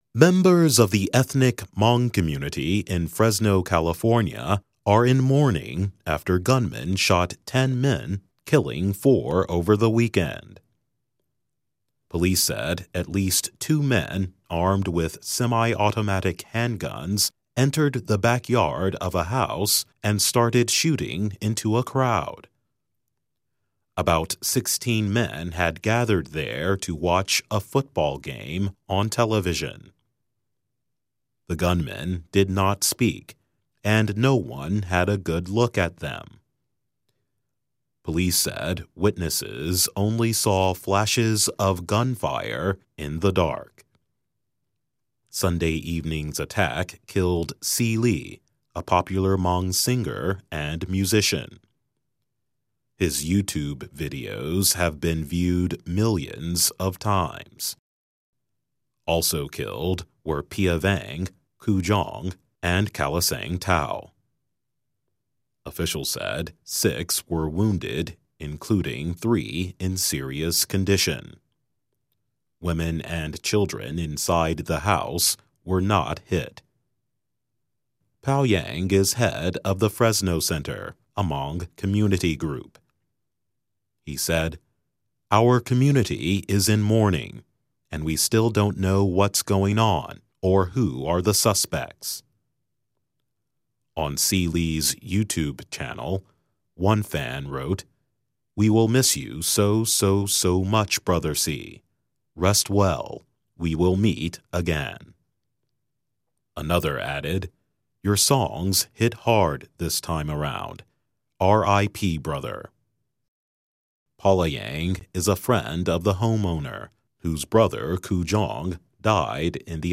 慢速英语:加州苗族社区发生致命枪击案造成四人遇难|慢速英语|慢速英语听力下载